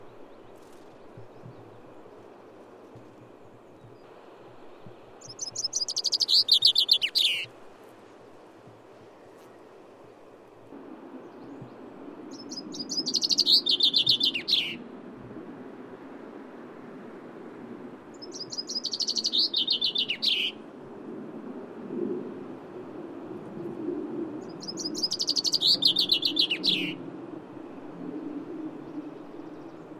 Photos de Pinson des arbres - Mes Zoazos
pinson-des-arbres.mp3